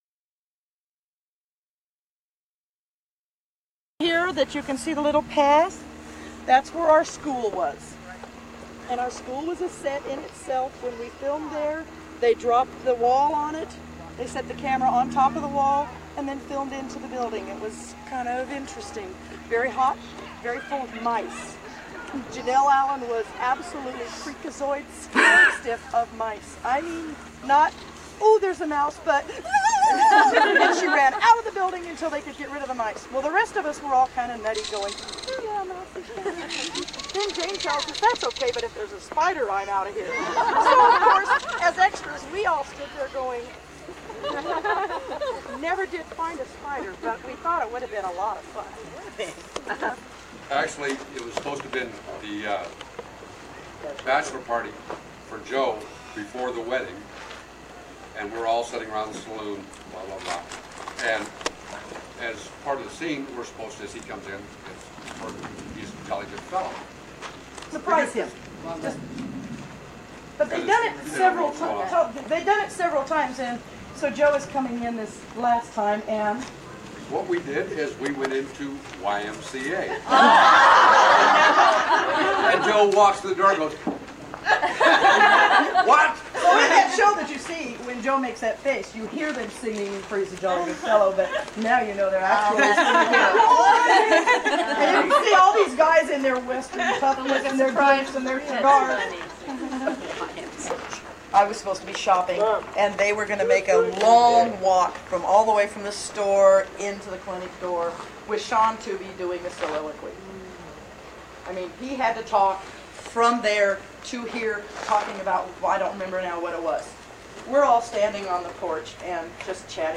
Gathering at the Paramount Ranch, members of the Coalition introduced themselves to each other.
Telling Stories- Real Media